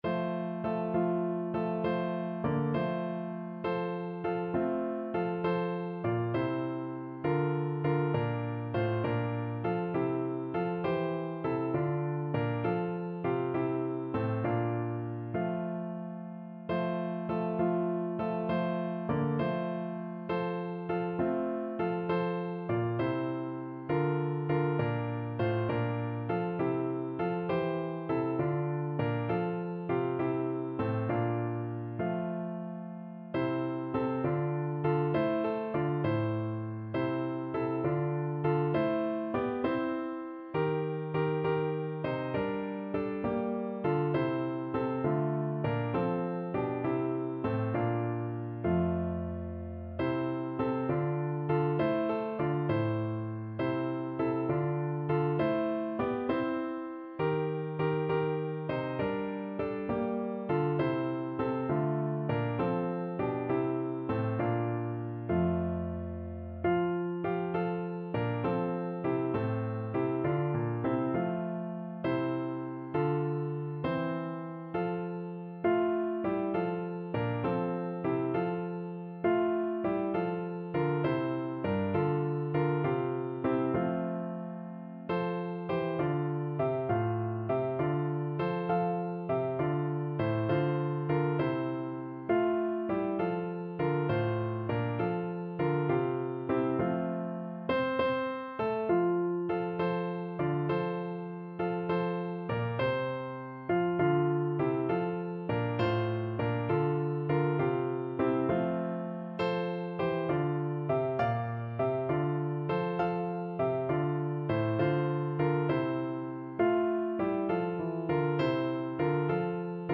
Classical Trad. Christ Was Born on Christmas Day Piano version
No parts available for this pieces as it is for solo piano.
F major (Sounding Pitch) (View more F major Music for Piano )
6/8 (View more 6/8 Music)
Classical (View more Classical Piano Music)
christ_was_b_PNO.mp3